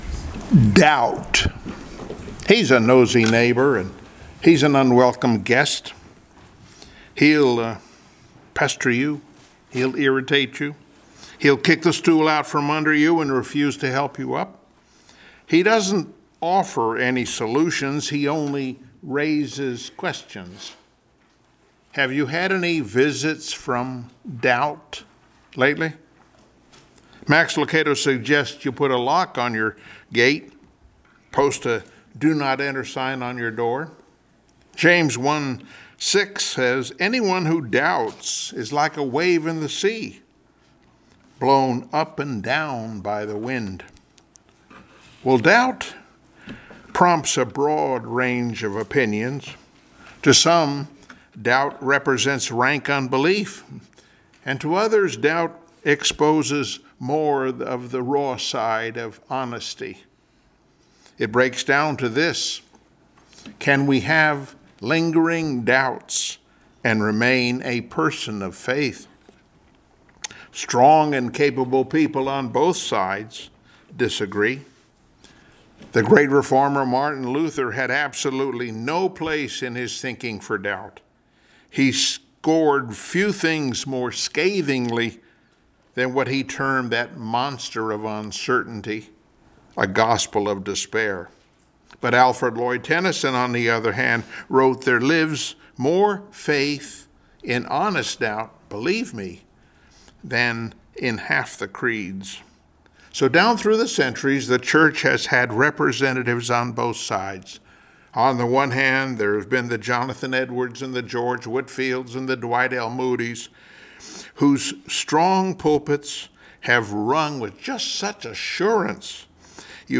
John 20:24-29 Service Type: Sunday Morning Worship Topics: Doubt vs Unbelief , Faith and Doubt coexist? , Path to Truth « “When the Lights Go Out” “Shame” »